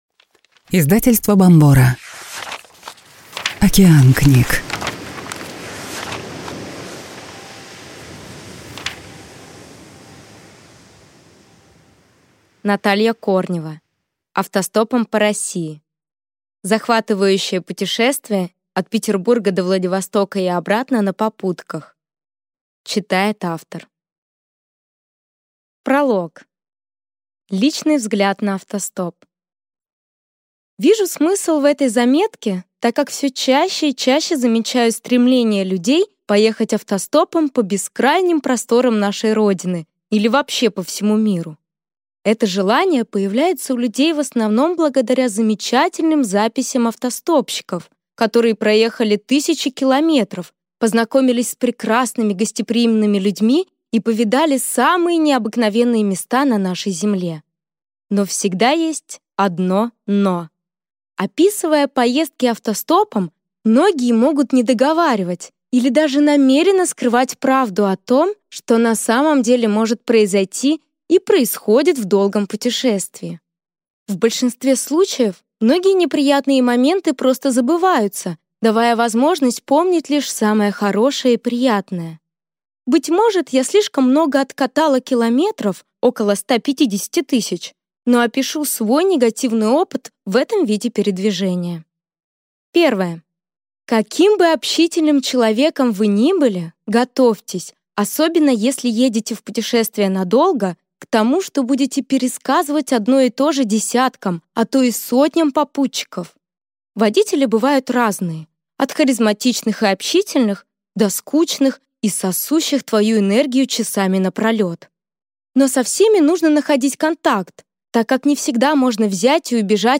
Аудиокнига Автостопом по России. Захватывающее путешествие от Петербурга до Владивостока и обратно на попутках | Библиотека аудиокниг